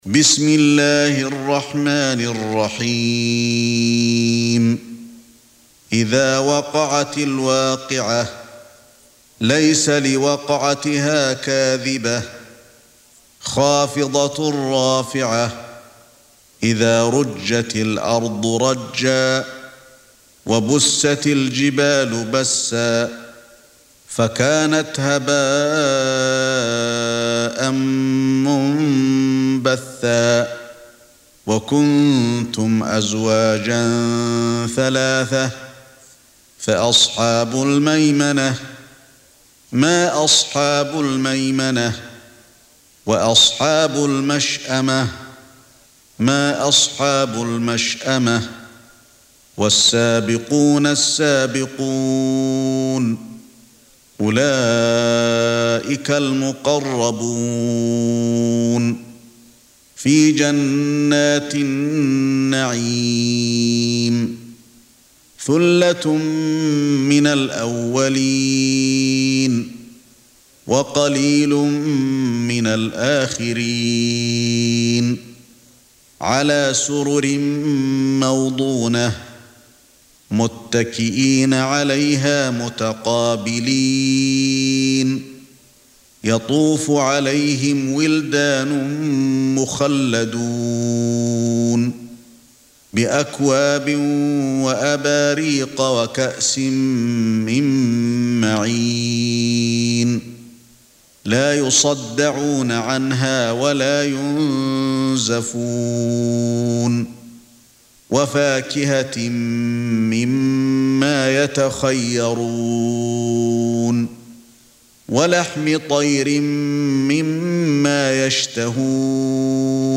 56. Surah Al-W�qi'ah سورة الواقعة Audio Quran Tarteel Recitation
Surah Sequence تتابع السورة Download Surah حمّل السورة Reciting Murattalah Audio for 56.